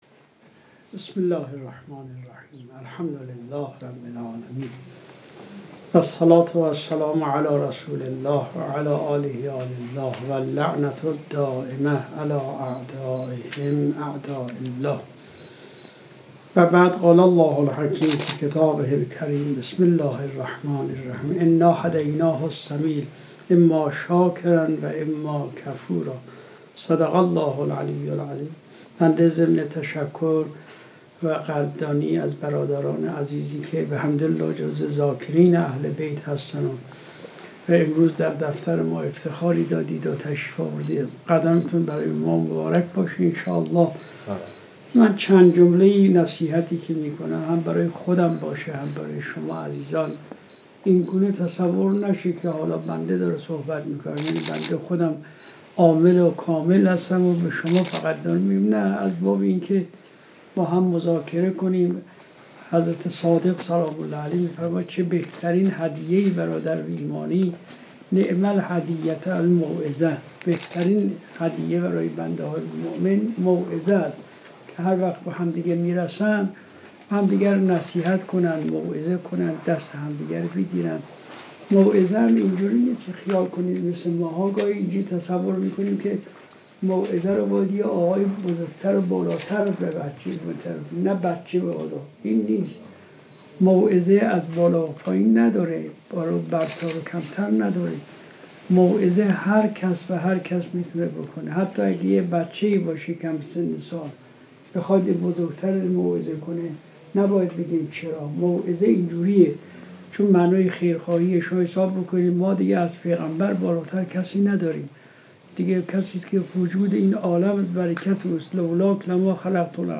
دیدار اهالی دارالمعرفت با حضرت آیت الله العظمی علوی گرگانی | دارالمعرفت اهل بیت عصمت و طهارت علیهم السلام
در روزی آکنده از عطر معنویت و در فضایی سرشار از صفا و اخلاص، دارالمعرفتی ها به محضر مرجع عالی‌قدر و فقیه اهل‌بیت، حضرت آیت‌الله العظمی علوی گرگانی (مدّ ظلّه العالی) شرفیاب شدند و از زلال بیانات ایشان بهره‌مند گردیدند.